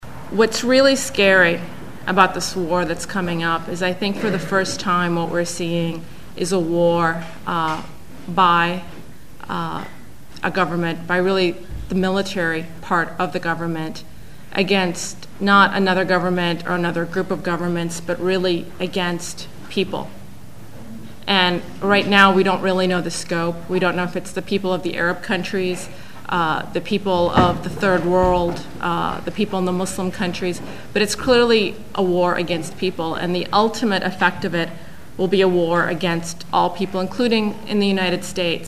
speech at the antiwar organizing meeting in Oakland 9/21/01